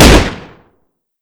dd44_fire1.wav